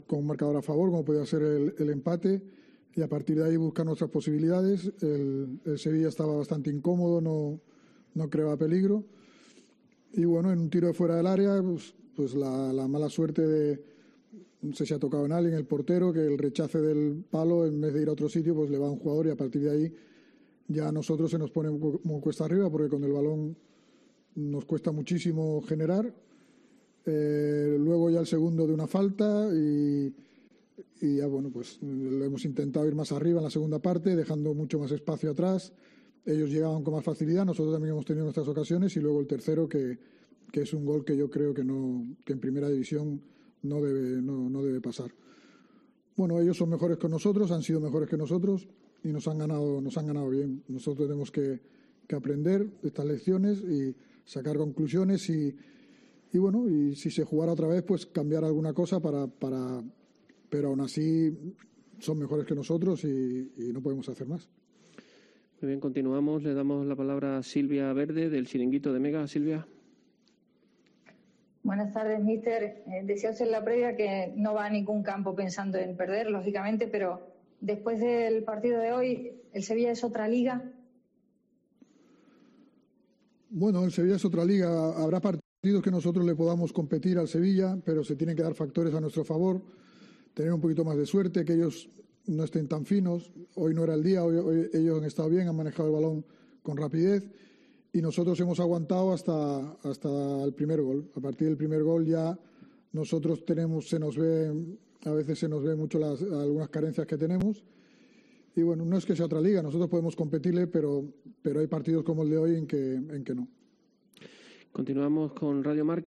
Álvaro Cervera tras el Sevilla - Cádiz